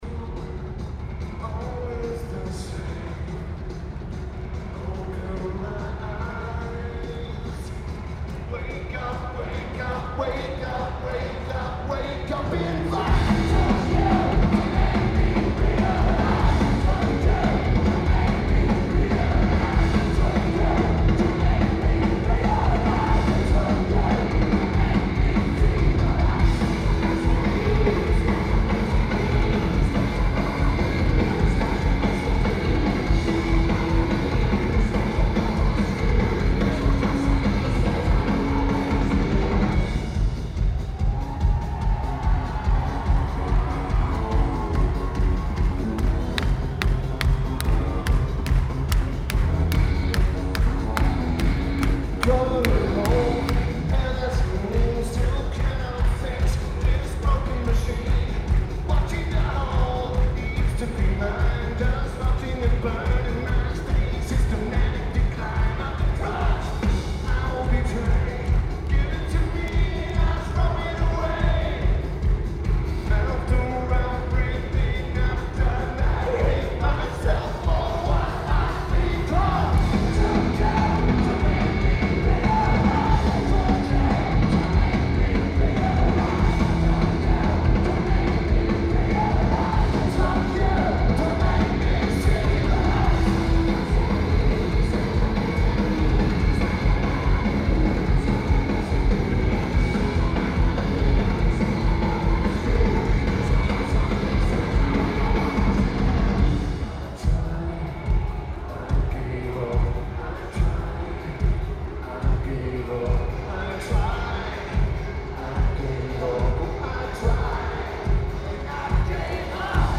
PNC Bank Arts Center
Lineage: Audio - AUD (Zoom H1)